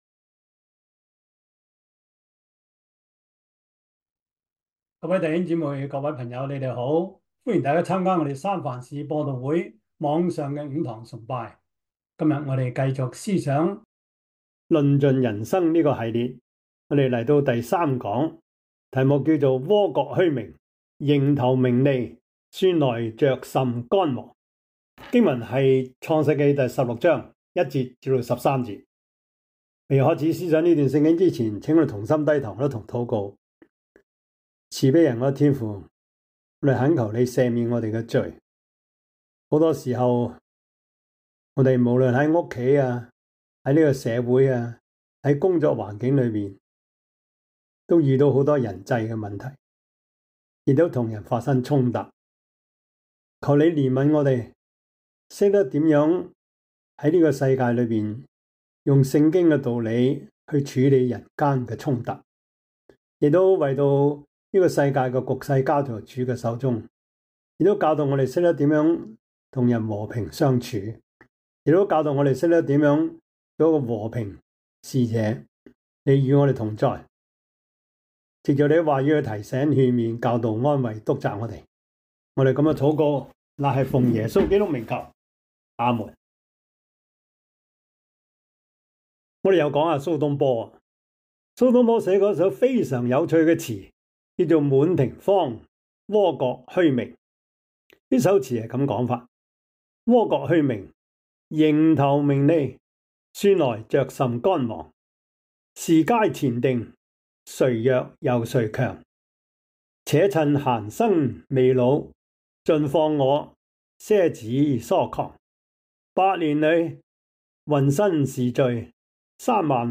創世記 16:1-13 Service Type: 主日崇拜 創世記 16:1-13 Chinese Union Version